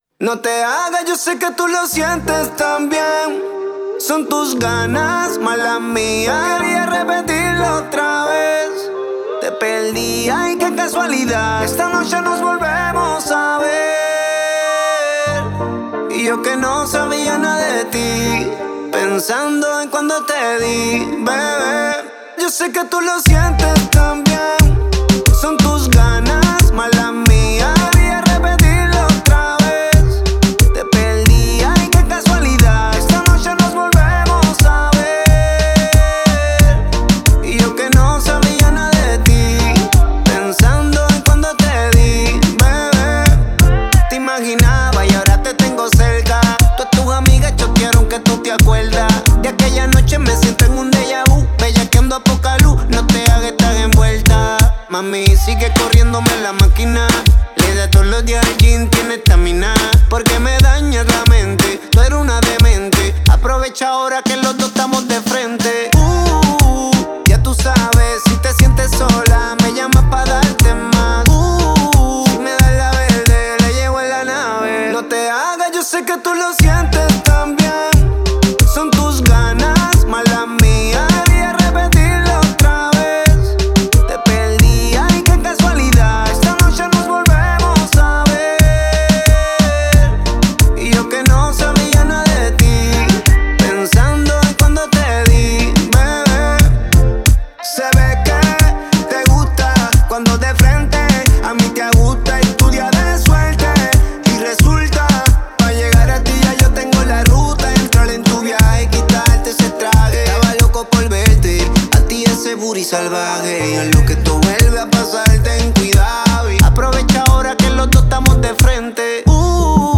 это зажигательный трек в жанре реггетон